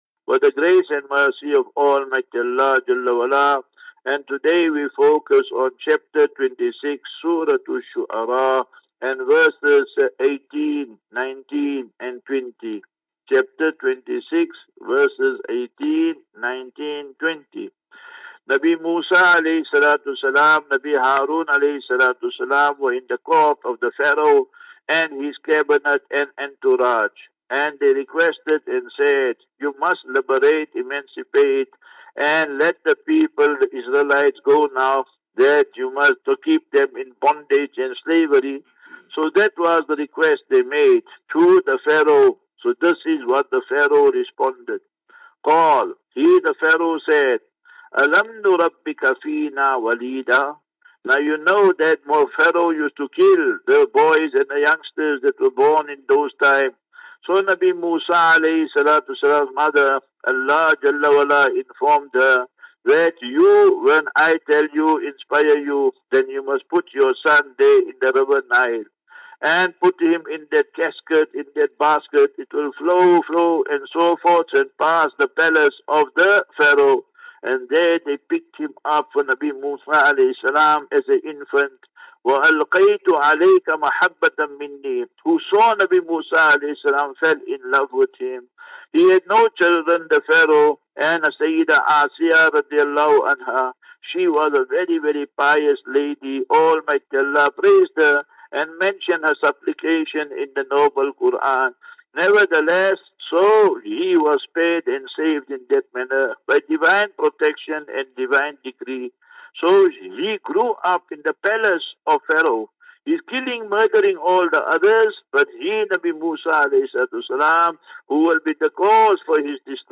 View Promo Continue Install As Safinatu Ilal Jannah Naseeha and Q and A 29 Apr 29 Apr 23 Assafinatu